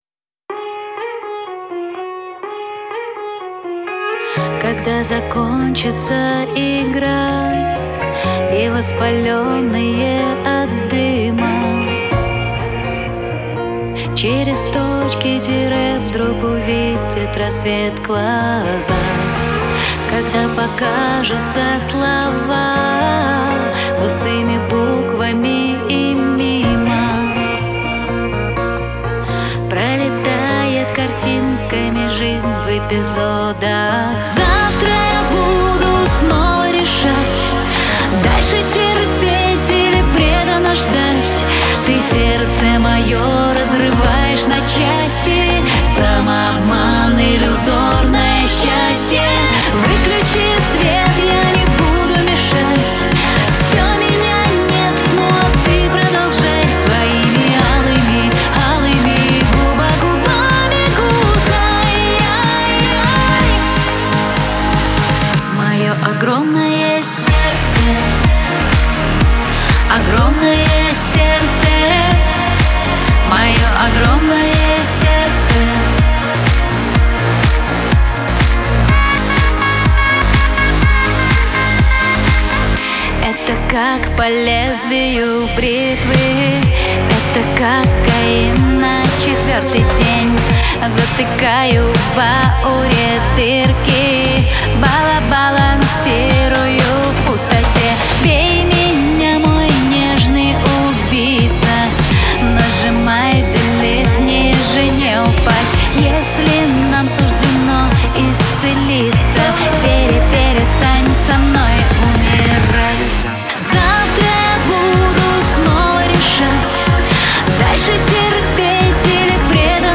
поп-балладу с яркими электронными элементами